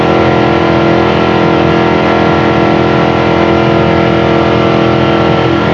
rr3-assets/files/.depot/audio/Vehicles/v8_nascar/nascar_mid_6000.wav
nascar_mid_6000.wav